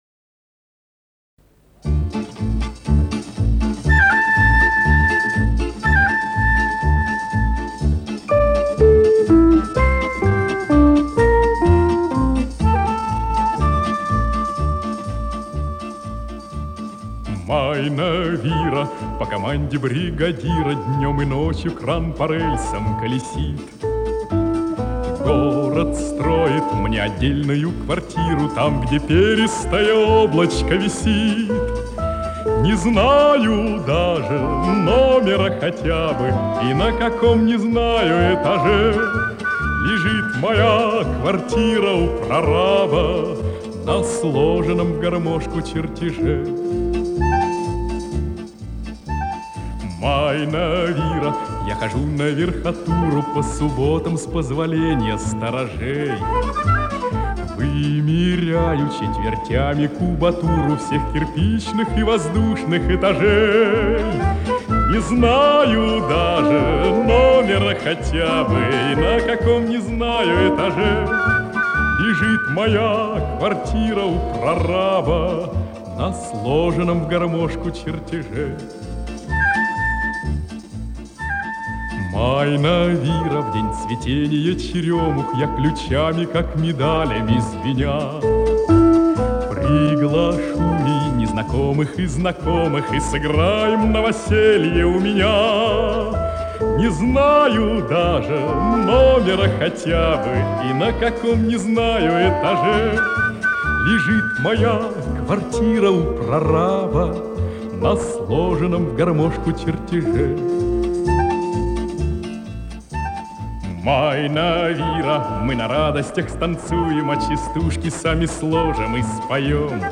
с магнитофильма